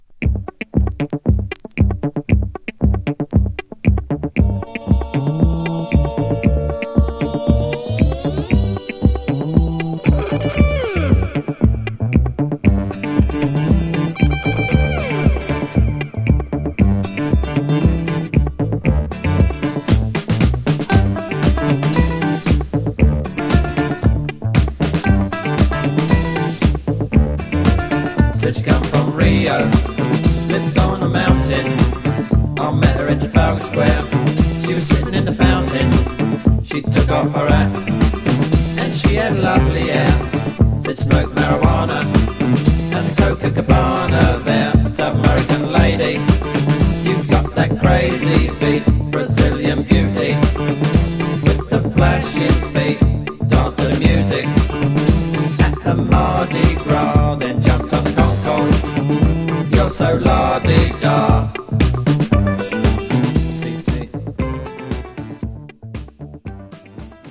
エレクトロ・アフロ・ディスコ・ポップ12″。プロモスぺシャル・ロングエディット。